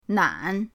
nan3.mp3